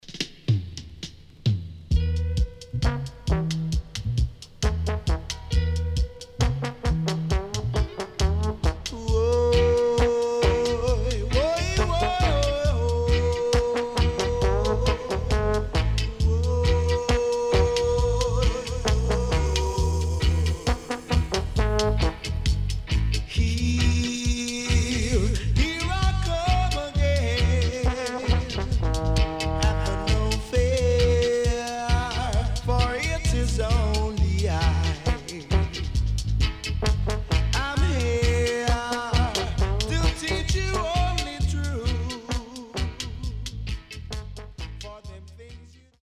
SIDE A:少しチリノイズ入りますが良好です。